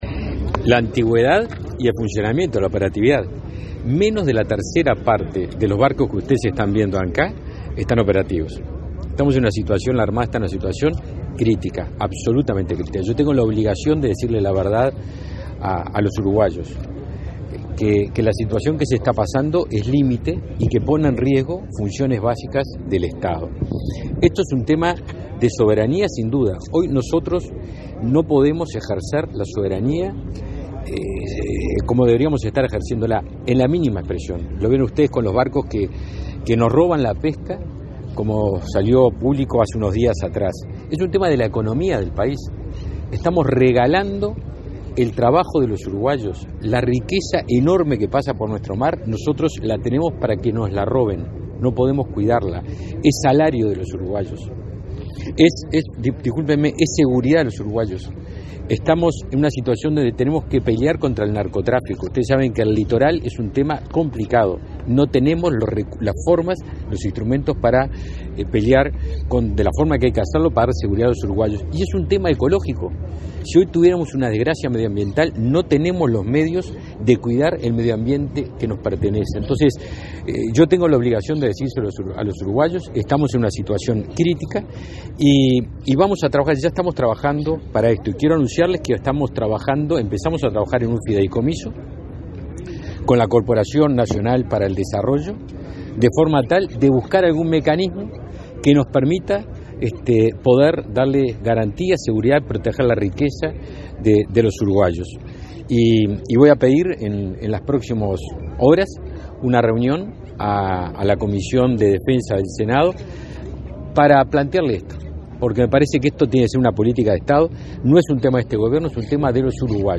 El ministro Javier García informó que comenzó, junto con la Corporación Nacional para el Desarrollo, un proceso para conformar un fideicomiso que permita renovar la flota de la Armada, que está en una situación crítica. “Son naves adecuadas para patrullar y defender a los uruguayos del narcotráfico y la pesca ilegal, y la ecología”, dijo el jerarca, en el aniversario de las Fuerzas del Mar de la Armada Nacional.